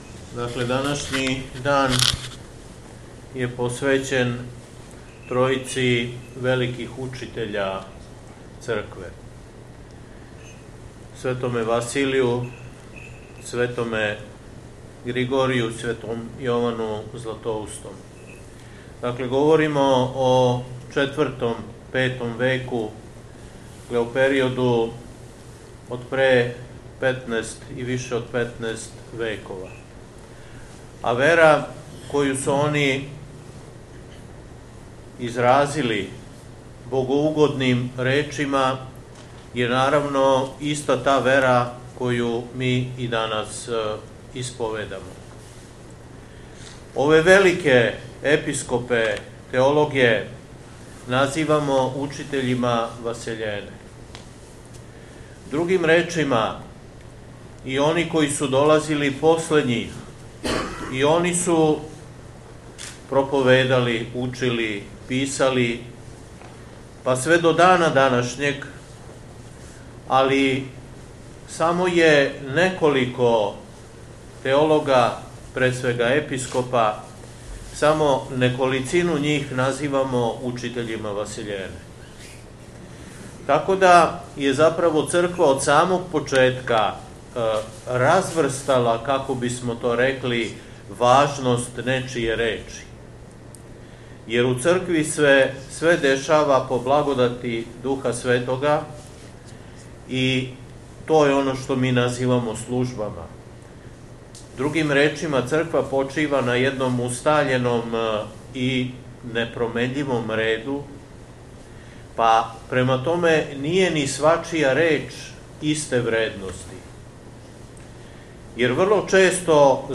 У среду, 12. фебруара 2025. године, када прослављамо Света Три Јерарха (Светог Василија Великог, Светог Григорија Богослова и Светог Јована Златоустог), Његово Високопреосвештенство Митрополит шумадијски г. Јован служио је Свету Архијерејску Литургију у малој Цркви у Тополи, а поводом храмовне славе...
Беседа